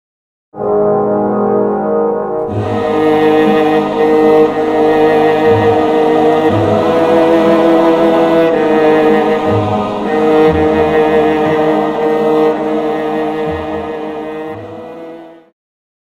Viola
Orchestra
Instrumental
Only backing